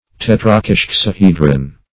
Search Result for " tetrakishexahedron" : The Collaborative International Dictionary of English v.0.48: Tetrakishexahedron \Tet`ra*kis*hex`a*he"dron\, n. [Gr.